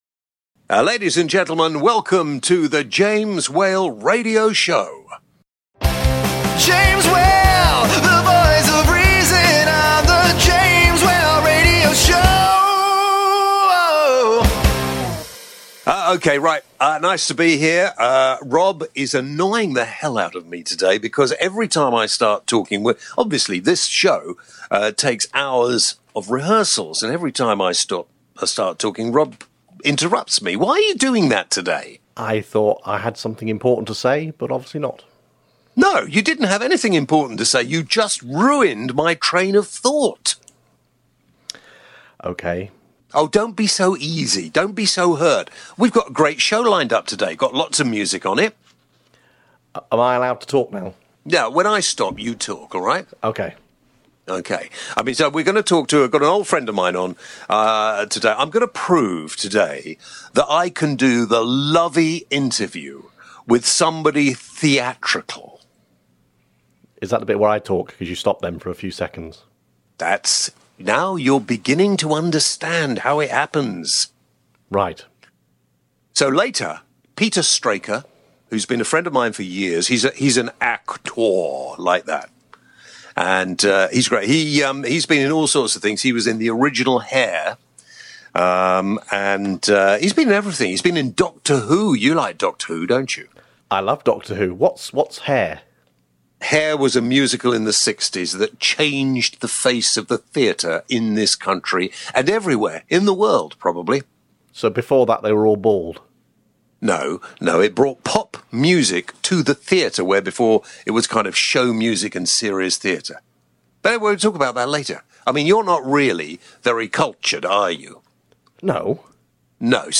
This week James chats to Peter Straker – Actor and Singer Also on the show, What was your first Job?, Ways of making money,and a website for talentless People.